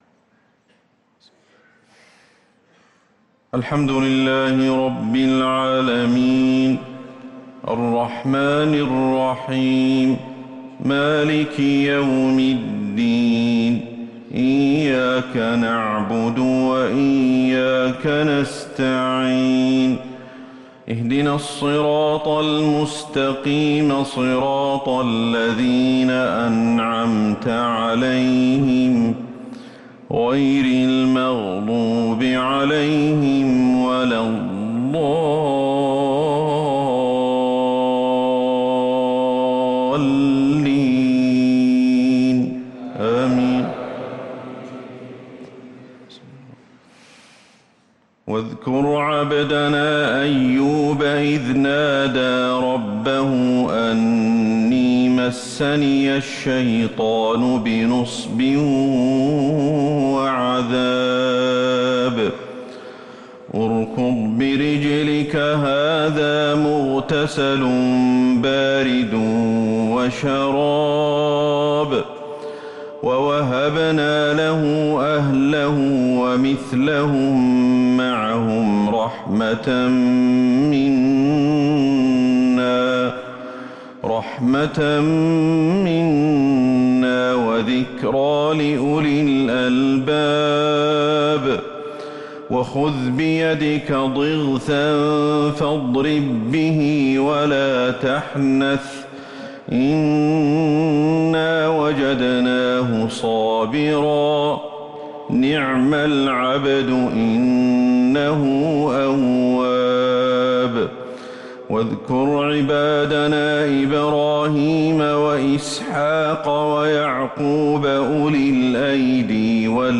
صلاة الفجر للقارئ أحمد الحذيفي 23 ذو الحجة 1444 هـ
تِلَاوَات الْحَرَمَيْن .